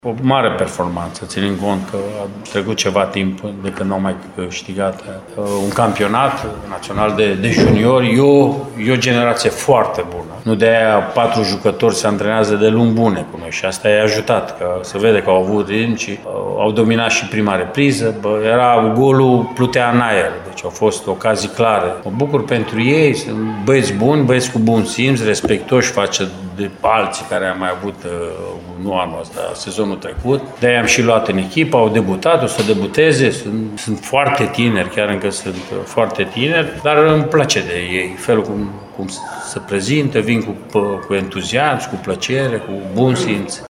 Antrenorul Mircea Rednic spune că va continua să le acorde o atenție specială juniorilor U17 ai clubului, care au devenit campioni duminică, în Liga Elitelor: